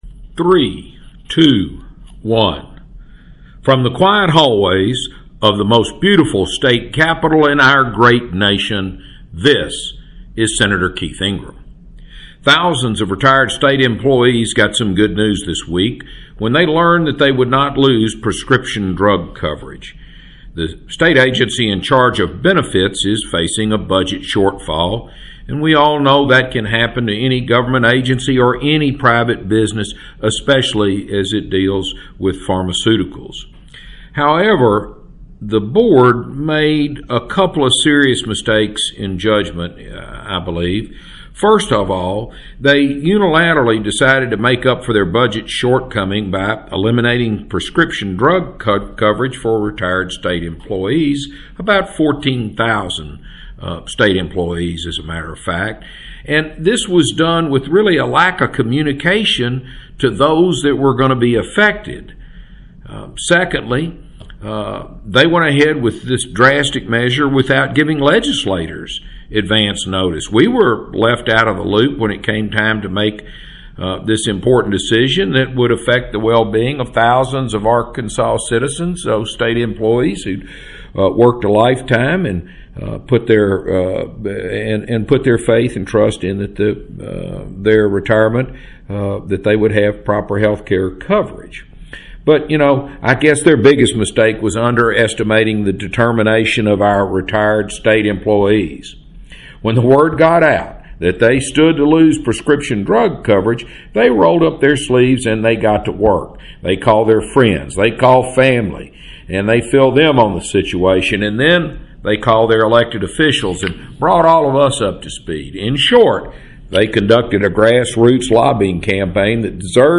Sen. Keith Ingram's Weekly Address – October 1, 2020